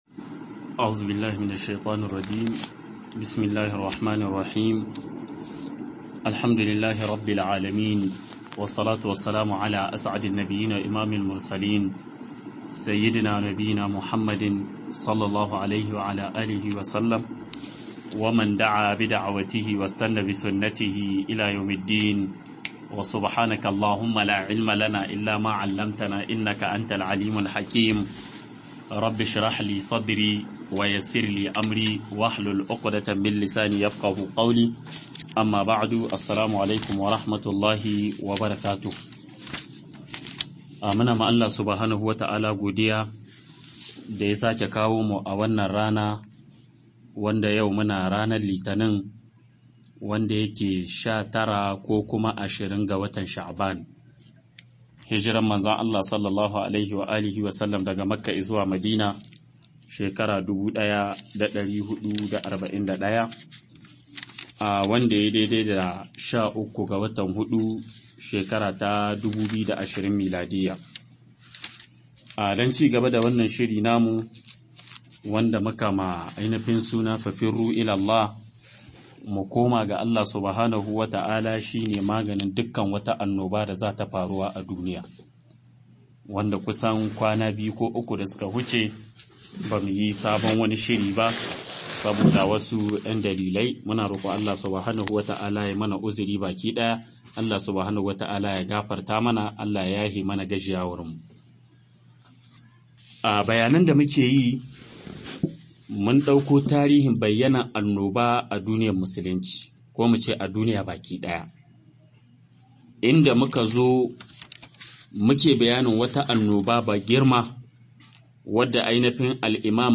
MUKOMA-ZUWA-GA-ALLAH-SHINE-MAFITA-06 - MUHADARA